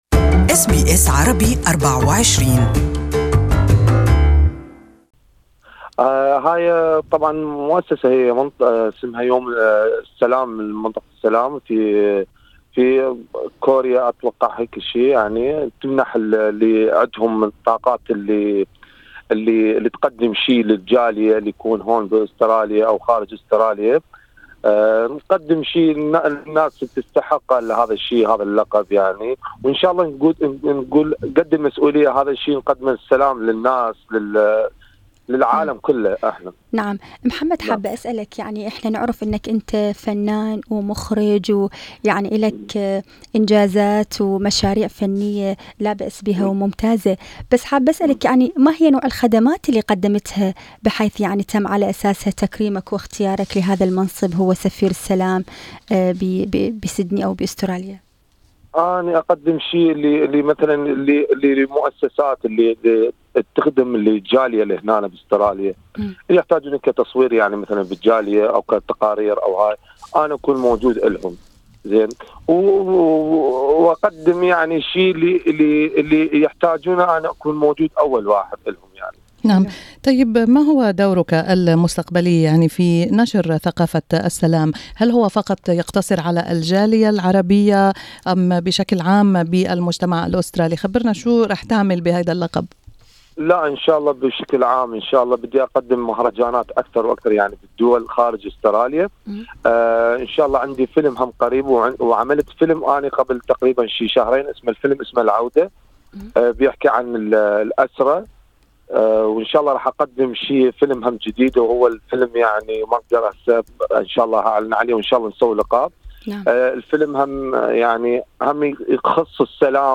This interview is only available in Arabic.